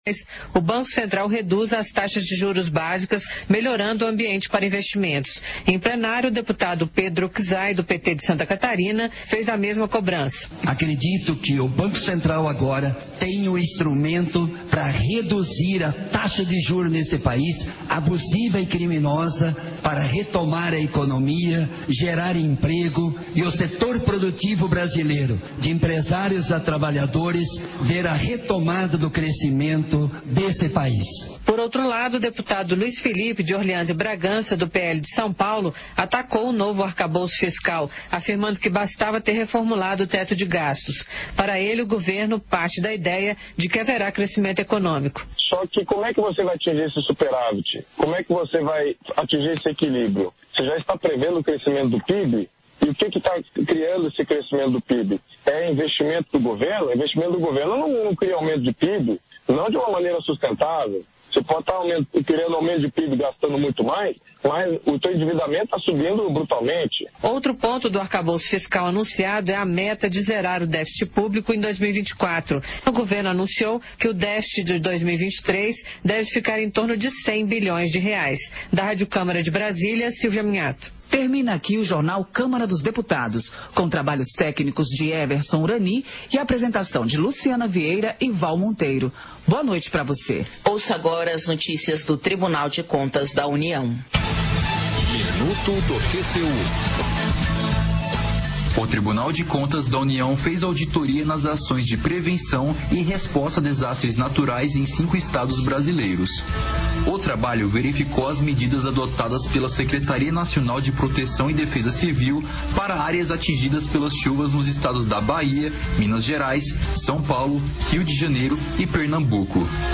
Sessão Ordinária 08/2023